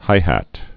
(hīhăt) Informal